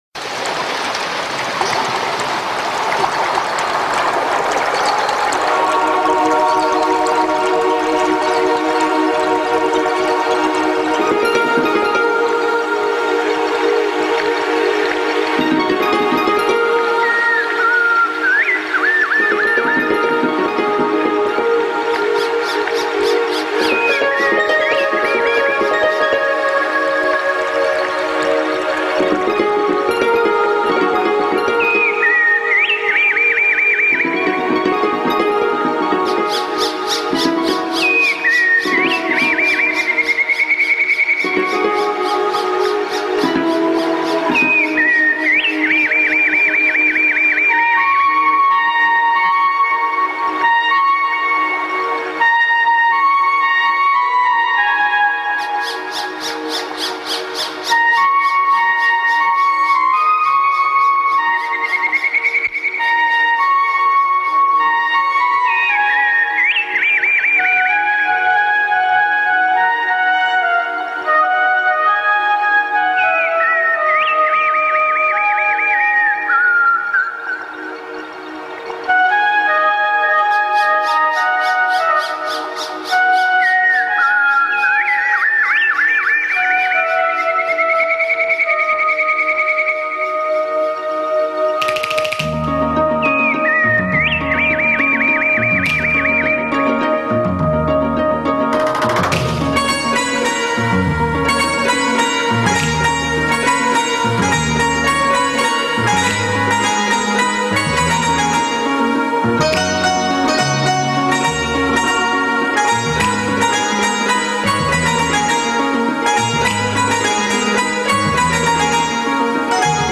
موسیقی فولکلور ایرانی